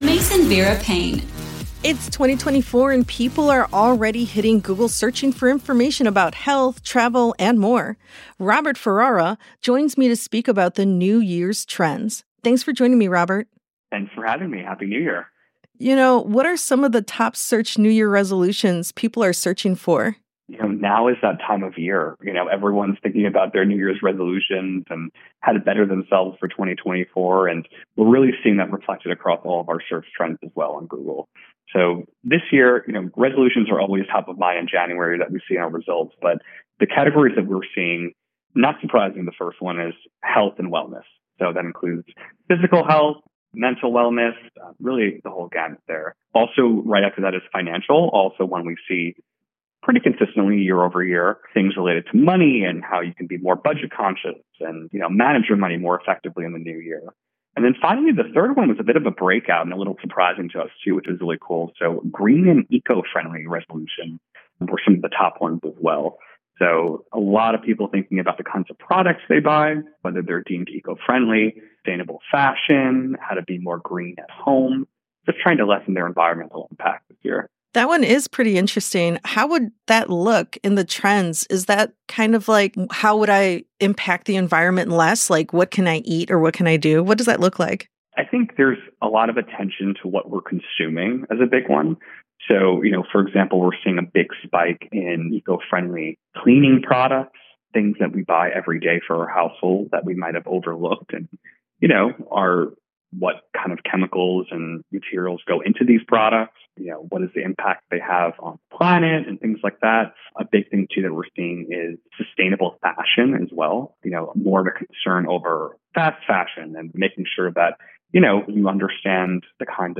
Google New Years Trends Transcription